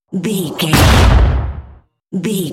Dramatic hit explosion
Sound Effects
Atonal
heavy
intense
dark
aggressive